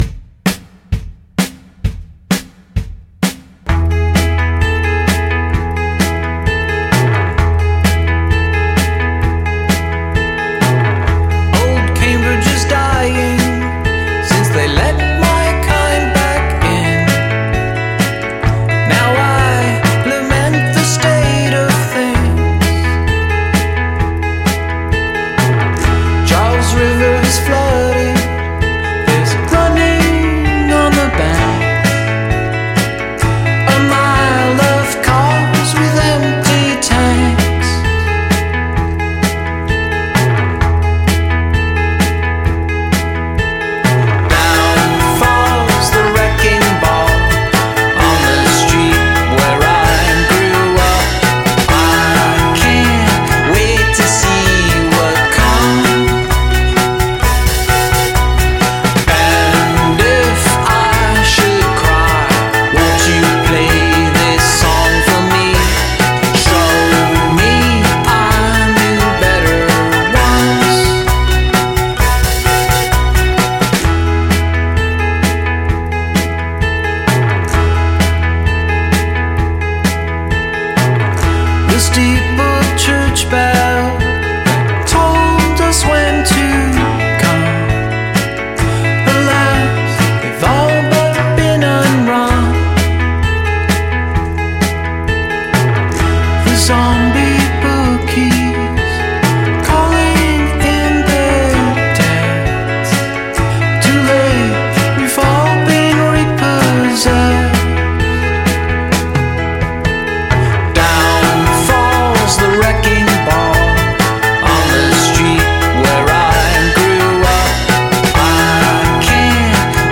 rock band